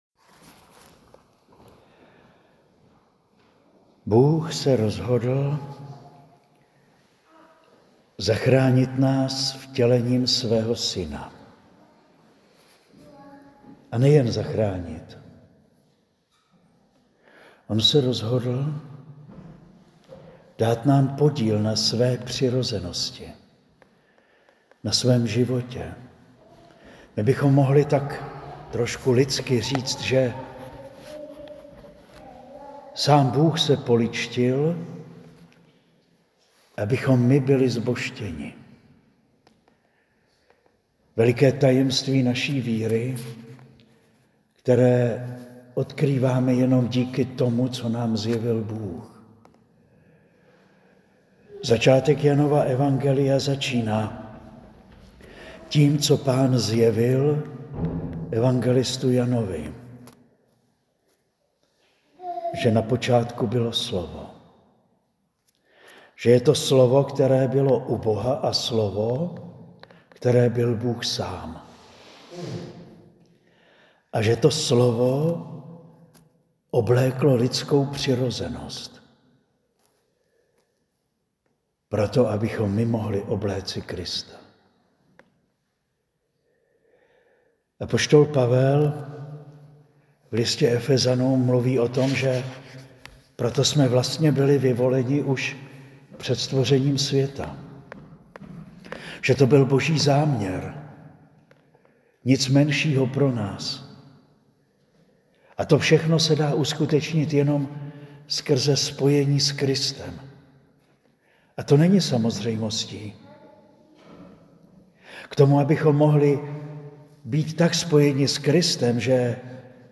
Kázání zaznělo o 2. neděli po Narození Páně dne 4. 1. 2026 a můžete si ho stáhnout zde.